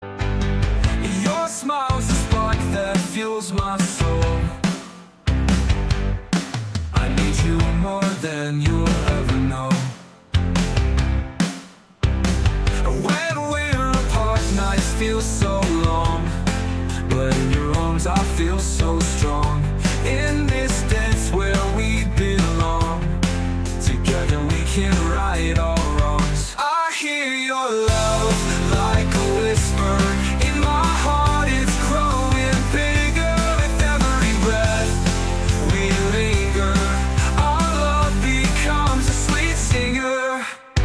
Kategoria POP